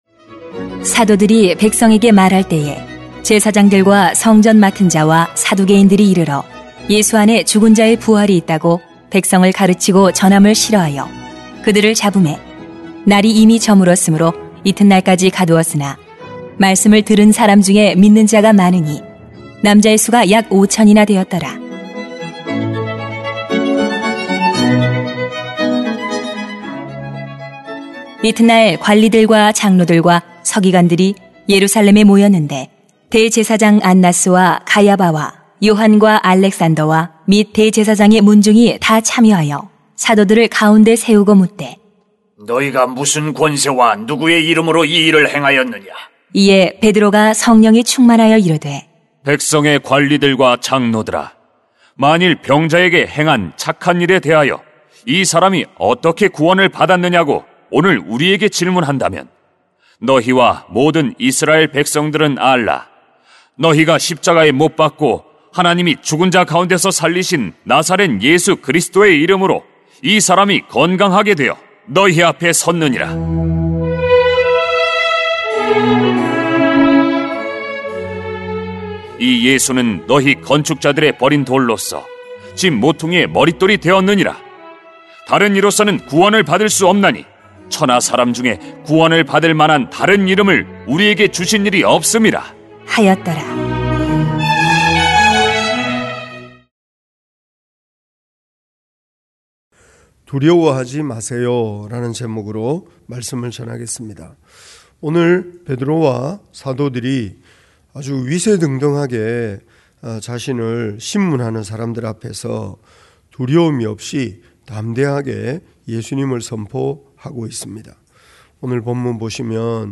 [행 4:1-12] 두려워하지 마세요 > 새벽기도회 | 전주제자교회